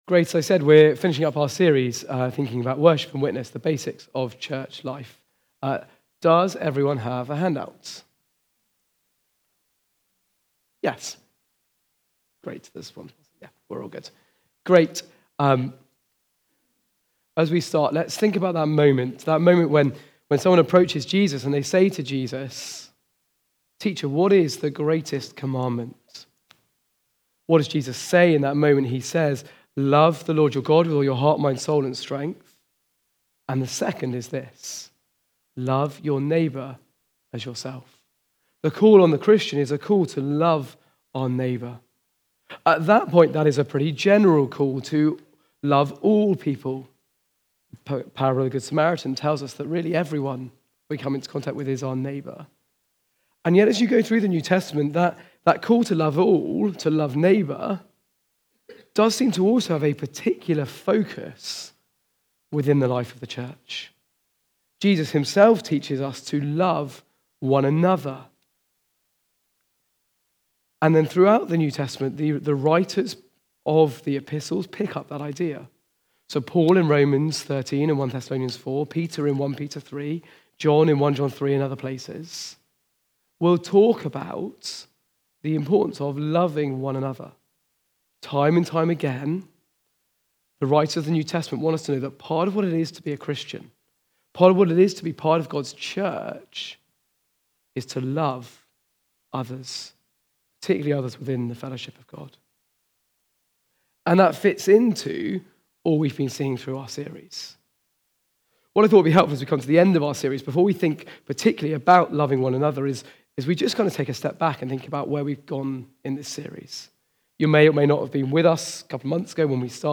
Preaching
Recorded at Woodstock Road Baptist Church on 01 December 2024.